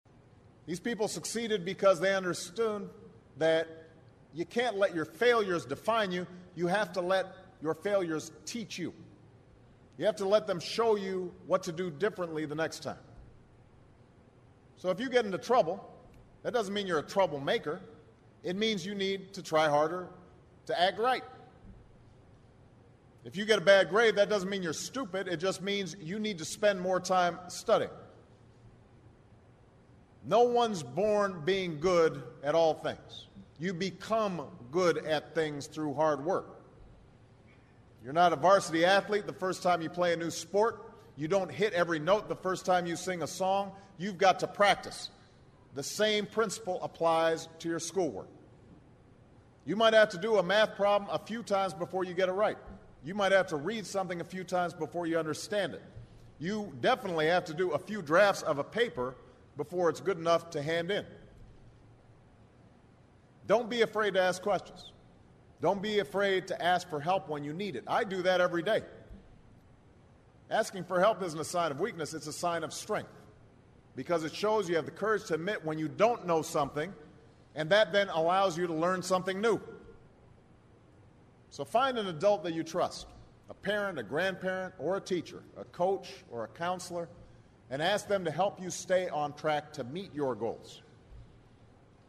名人励志英语演讲 第9期:梦想与责任(9) 听力文件下载—在线英语听力室